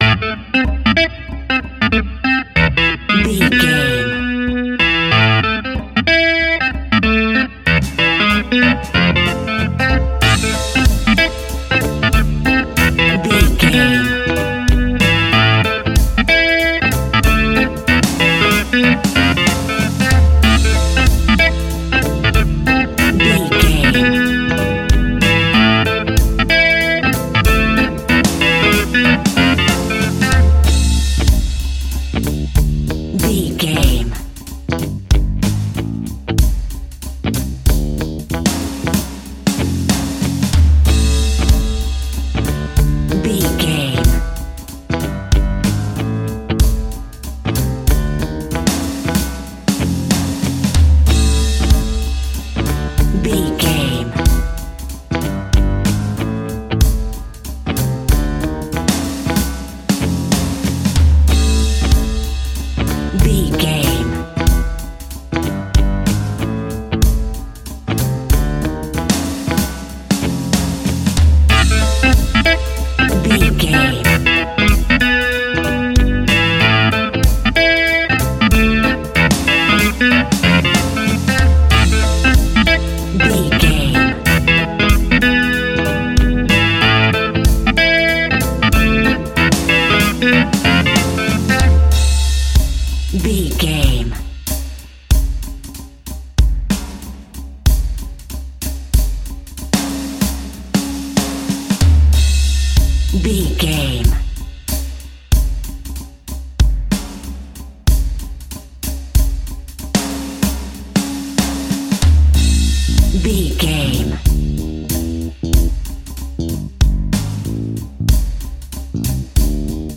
Aeolian/Minor
reggae music
laid back
chilled
off beat
drums
skank guitar
hammond organ
percussion
horns